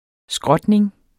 Udtale [ ˈsgʁʌdneŋ ]